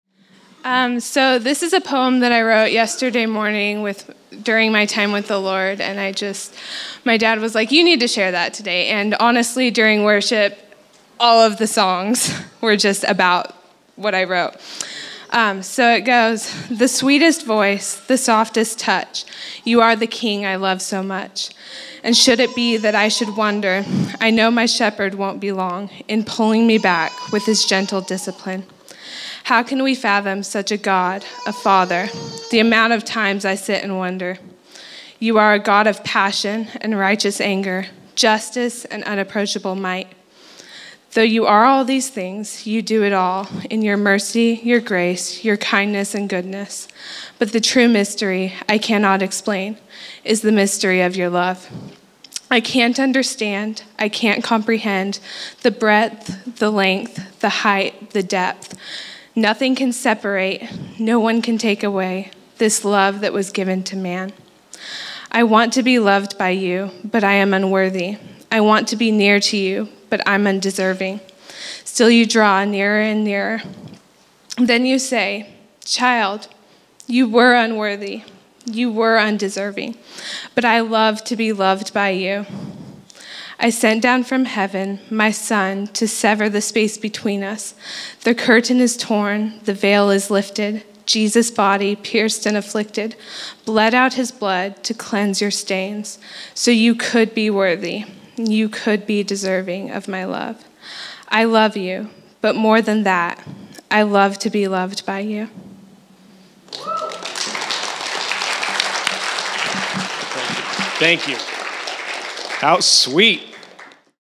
Poem: I Love to be Loved by You
Location: El Dorado